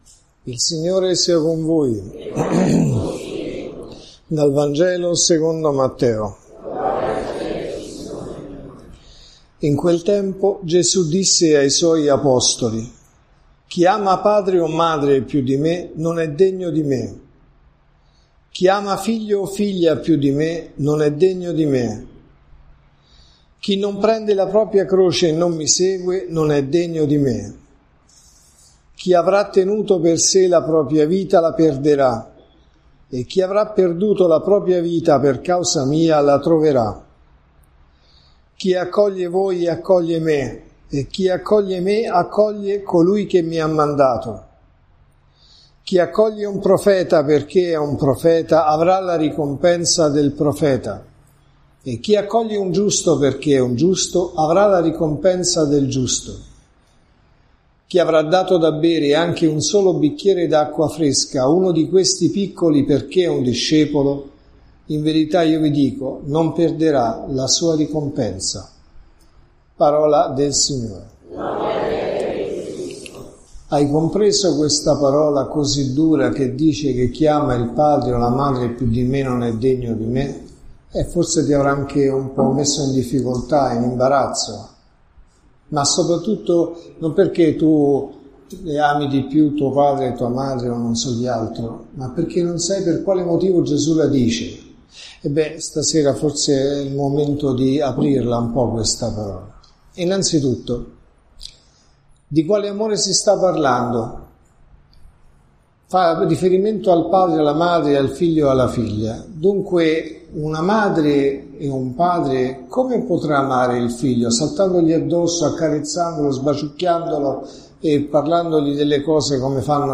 Chi non prende la croce non è degno di me. Chi accoglie voi, accoglie me.(Messa del mattino e della sera)